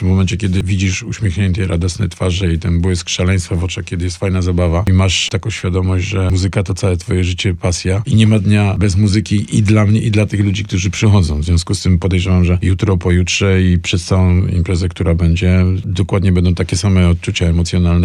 Gwiazdy polskiej sceny muzycznej, Jacek Stachursky i Paweł Lipski, czyli Nowator są już w Stanach Zjednoczonych i odwiedzili studio Radia Deon.